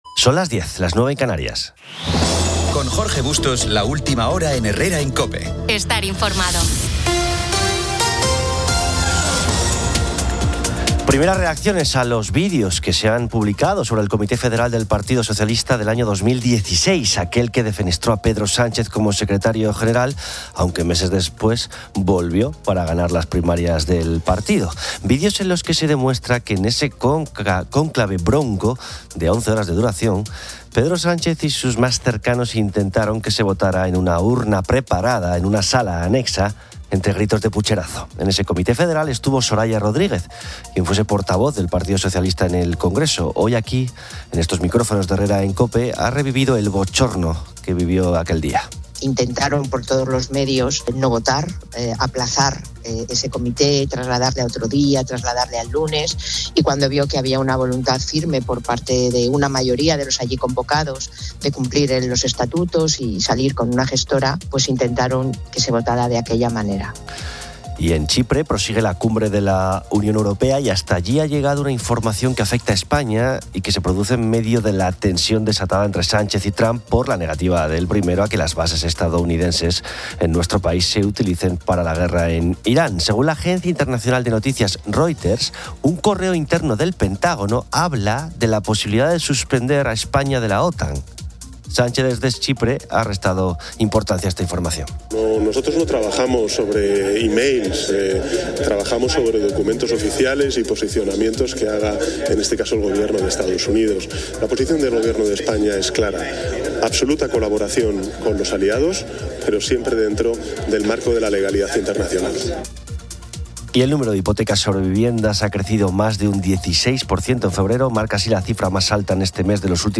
Finalmente, el espacio deportivo cubre LaLiga con el partido Betis-Real Madrid, el Gran Premio de Jerez de motos, el Mutua Madrid Open de tenis y el baloncesto europeo. Una sección destacada del programa se centra en las populares "frases de madre y padre", donde los oyentes comparten anécdotas y expresiones familiares.